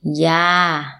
– yaa